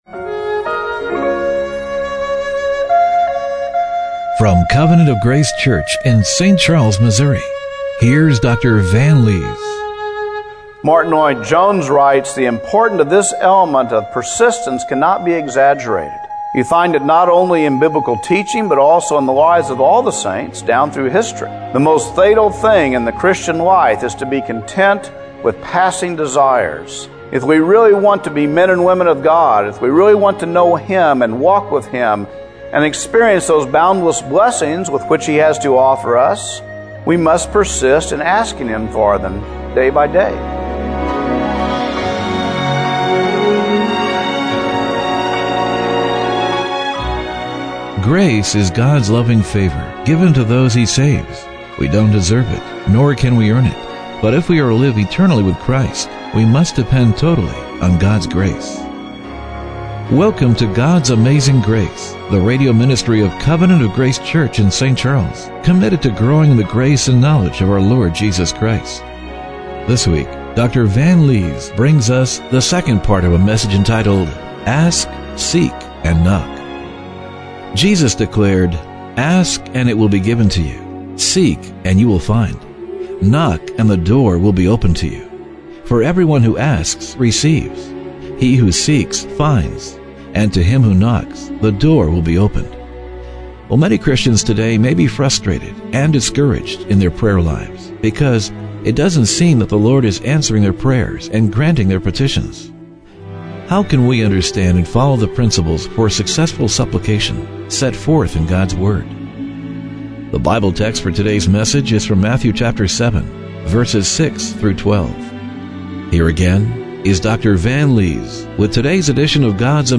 Matthew 7:6-12 Service Type: Radio Broadcast How can we understand and follow the principles for successful supplication set forth in God's word?